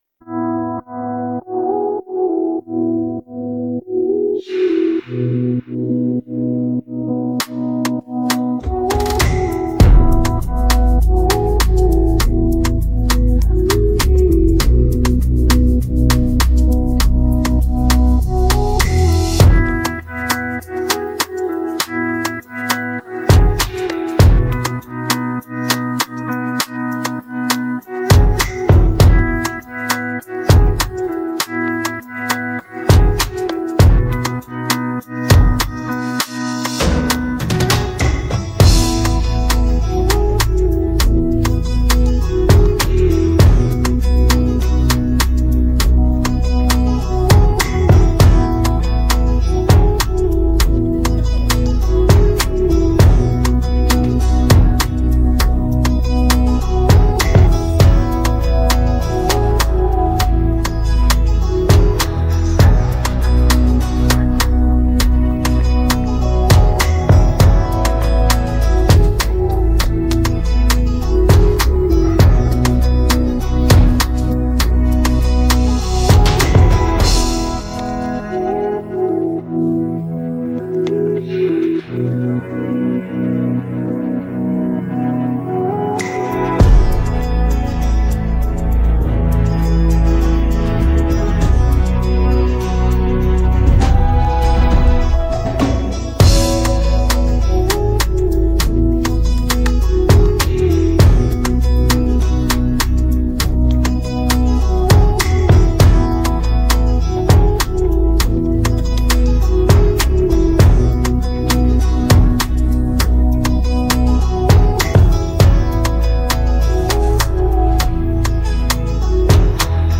Afro pop Afrobeats Highlife